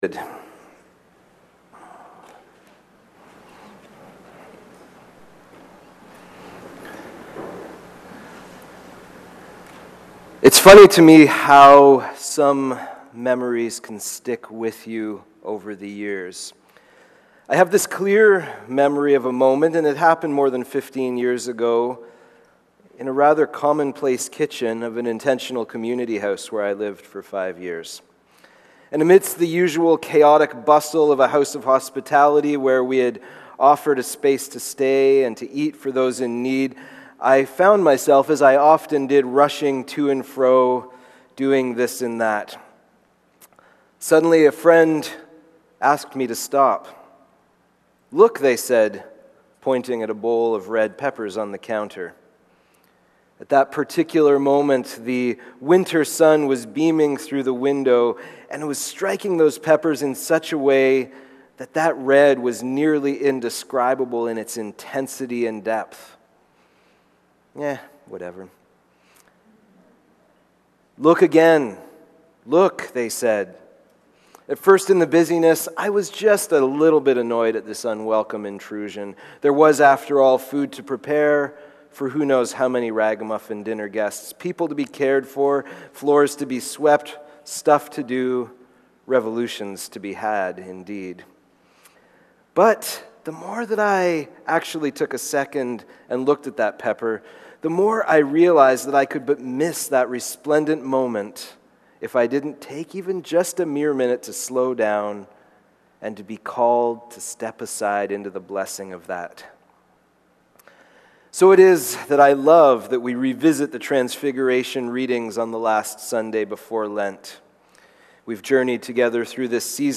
Sermons | St. Marys' Metchosin